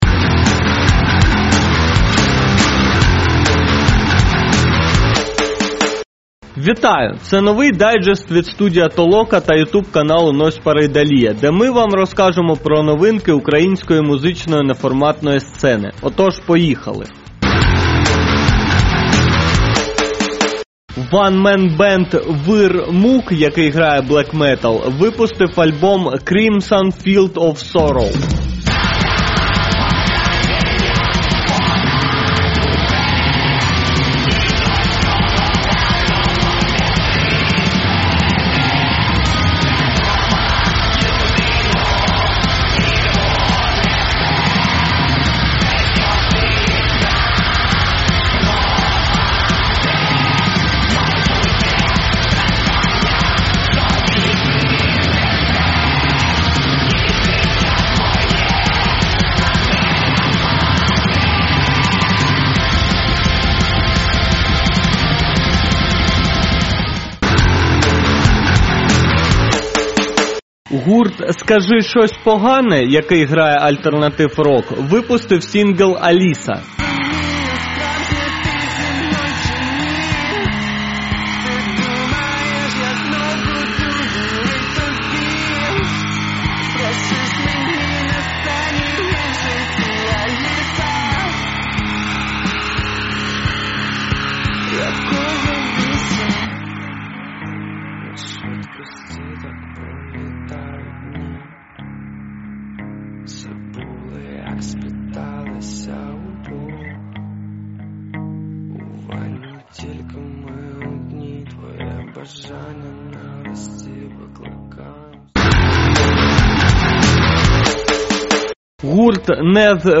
Стиль: Подкаст